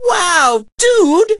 poco_hurt_01.ogg